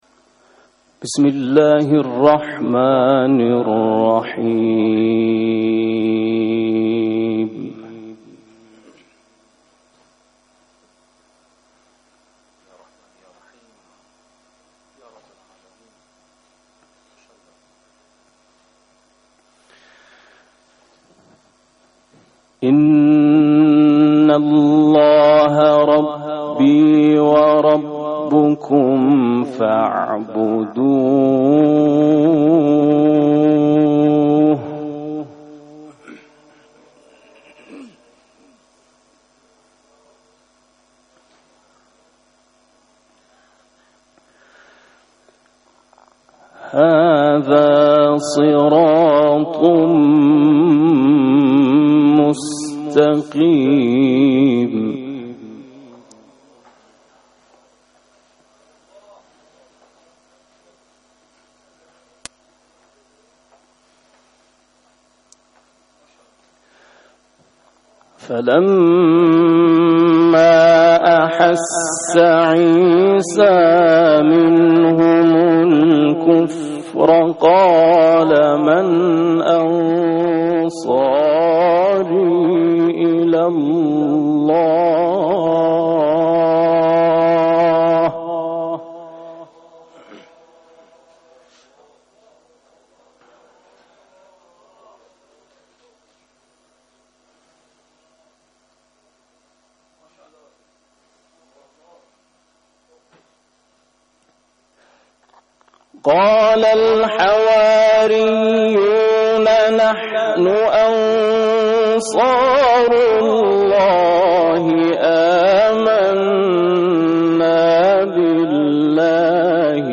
فایل صوتی شرکتکنندگان مرحله نیمهنهایی قرائت تحقیق مسابقات سراسری قرآن
گروه مسابقات: قرائت تحقیق اجرا شده توسط متسابقان مرحله نیمه نهایی مسابقات سراسری قرآن کریم که امروز در حال برگزاری است، ارائه میشود.